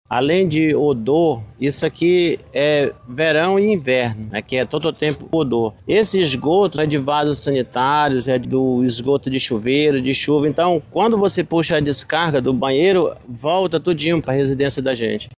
Um outro morador que também tem sua casa afetada pela tubulação de esgoto, relata que o problema é recorrente e que os resíduos fecais invadem sua casa até pelo banheiro.